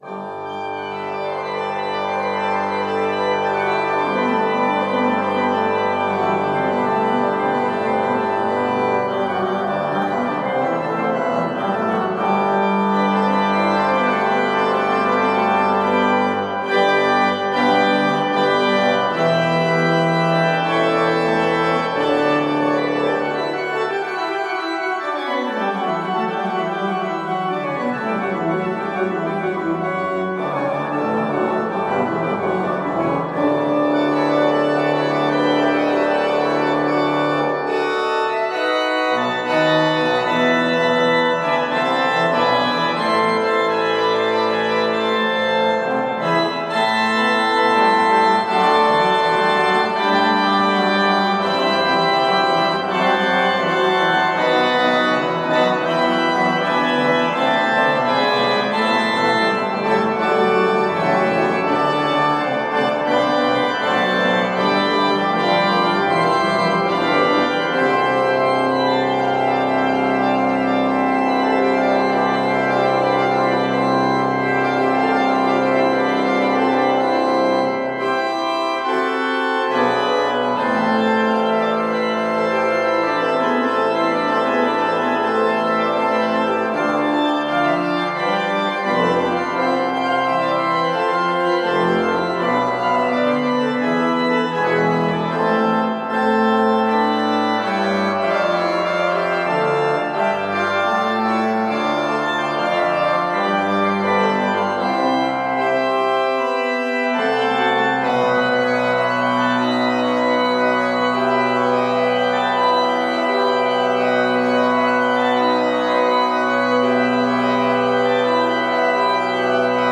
År 1990 ersattes den av en ny orgel, byggd av det svenska företaget Hammarberg.
LYSSNA PÅ ORGELEN